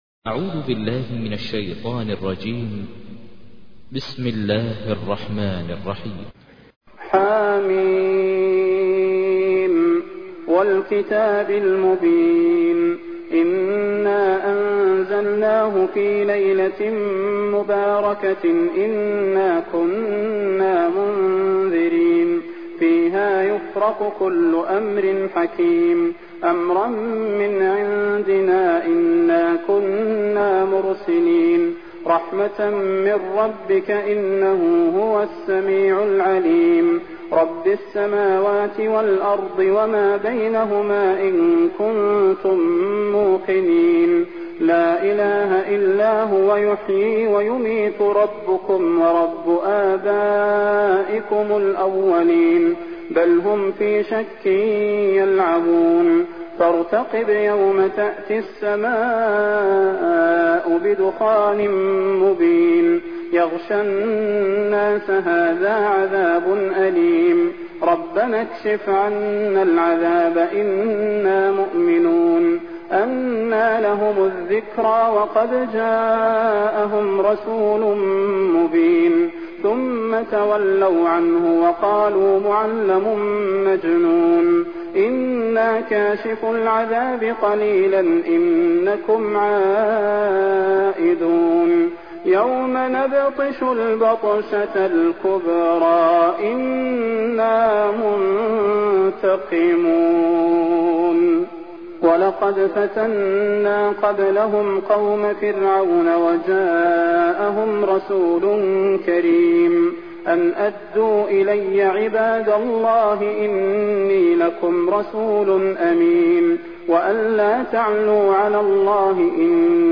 تحميل : 44. سورة الدخان / القارئ ماهر المعيقلي / القرآن الكريم / موقع يا حسين